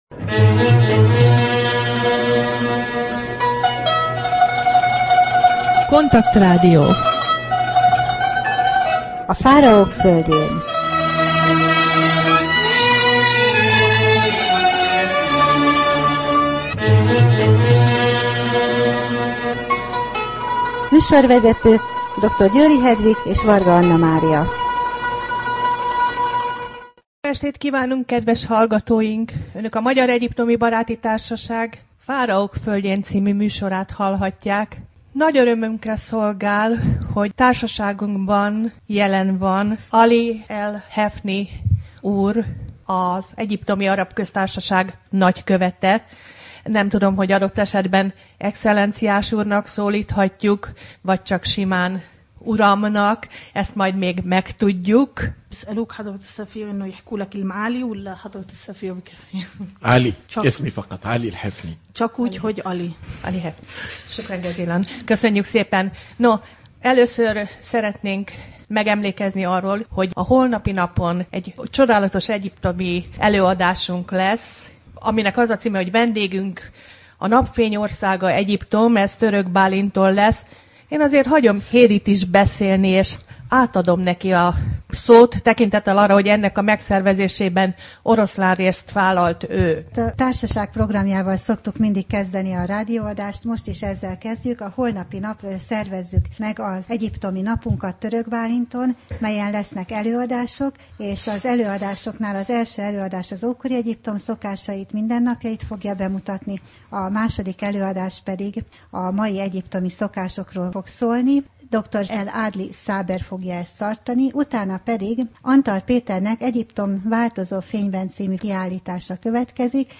Rádió: Fáraók földjén Adás dátuma: 2010, February 26 Fáraók földjén Wenamon rovat / KONTAKT Rádió (87,6 MHz) 2010 február 26.
Vendégünk Aly el-Hefny egyiptomi Nagykövet Úr
Az adás során számos hallgatói kérdés érkezett, és a Társaságban feltett kérdésekről is megkérdeztük.